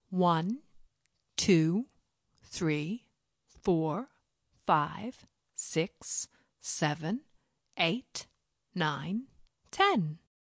描述：一个女人数1至10 CHERERFUL
Tag: 时间 数字 计数 女人计数 计数-1〜10